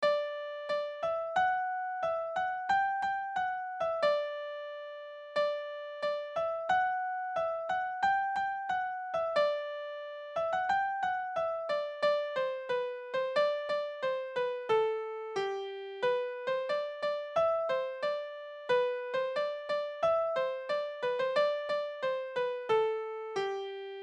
Morceaux de musique traditionnelle
irlandais70.mp3